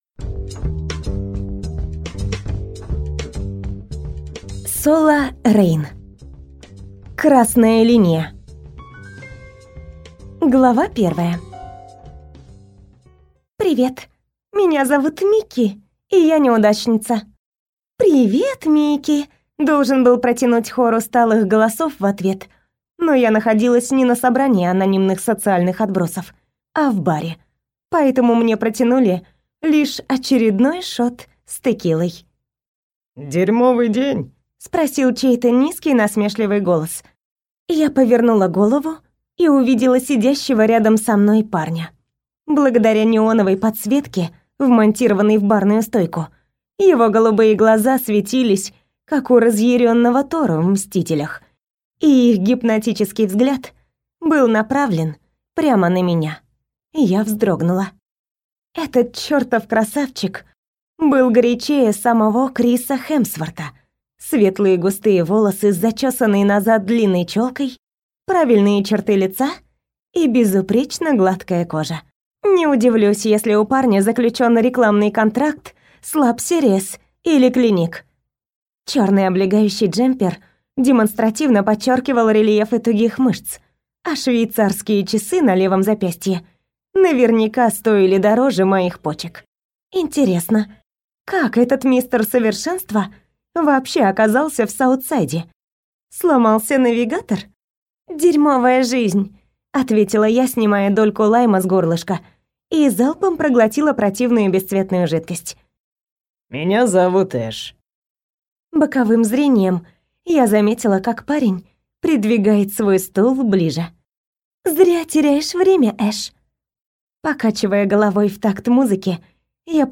Aудиокнига Красная линия